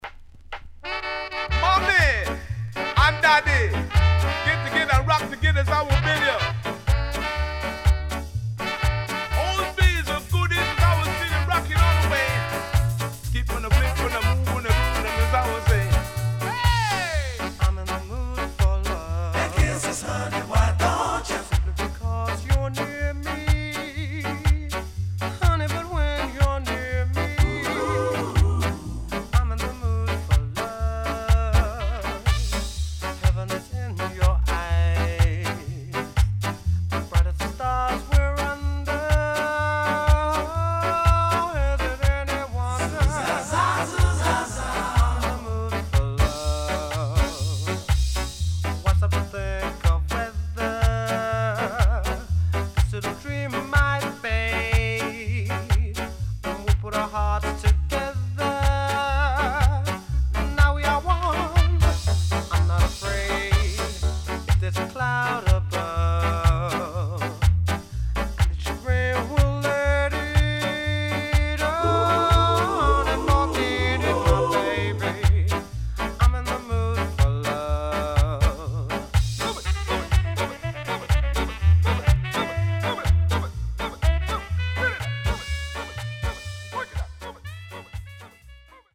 Sweet Vocal.W-Side Good